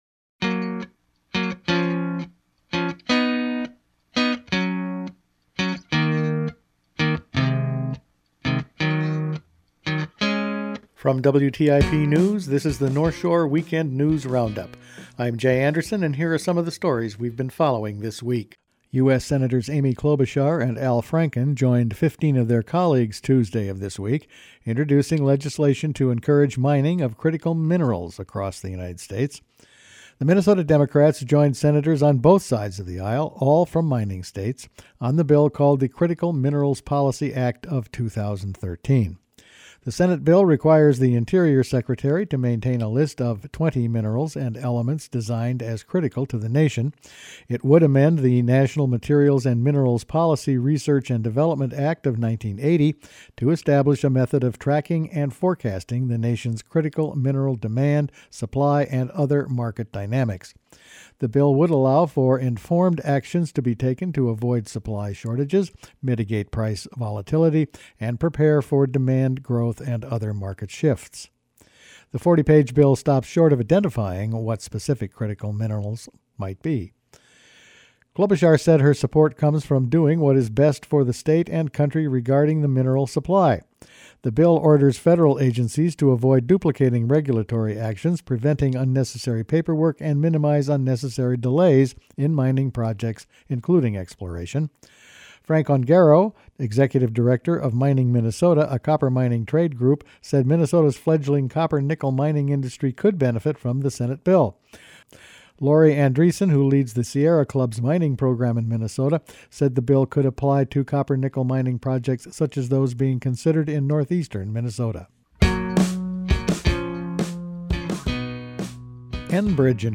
Attachment Size WrapFinalCut_110113.mp3 19.74 MB Each week the WTIP news staff puts together a roundup of the news over the past five days. Critical minerals, Asian carp, the Sandpiper oil line and charges brought against the County Attorney…all in this week’s news.